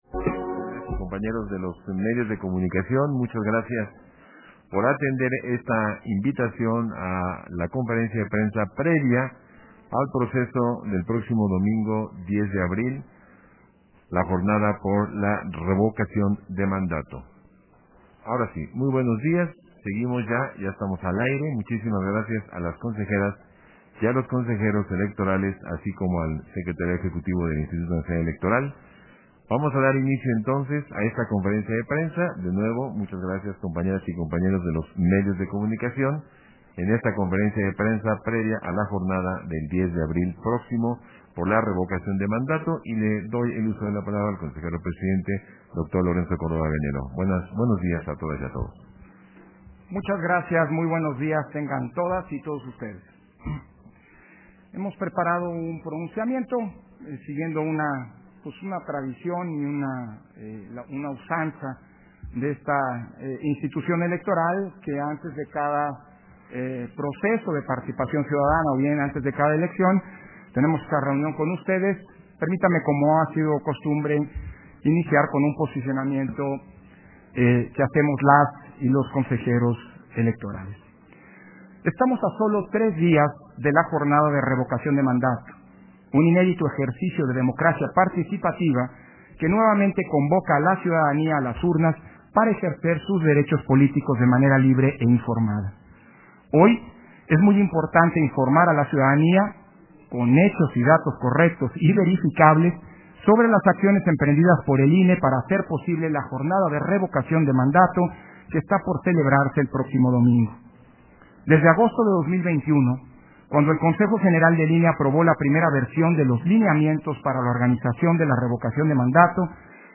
070422_AUDIO_CONFERENCIA-DE-PRENSA